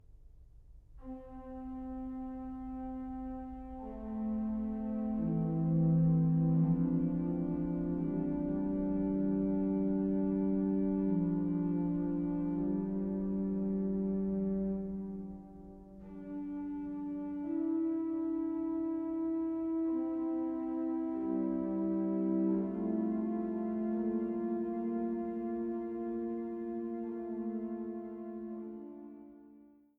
Jehmlich-Orgel in der Kirche St. Wolfgang zu Schneeberg